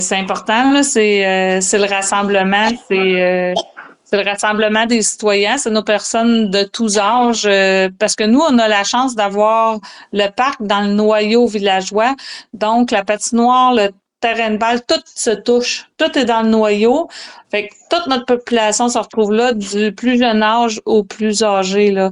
La mairesse, Sylvie Tanguay, a soutenu qu’un parc est essentiel pour une population.